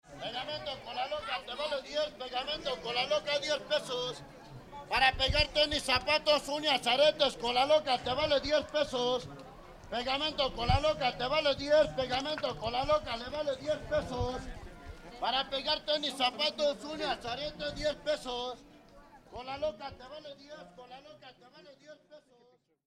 Kola Loka salesman, Mexico City
Kola Loka vendor at the Zócalo in Mexico City.
Today street vendors and performers fill up its surrounding streets creating an enormous outdoor market selling just about everything.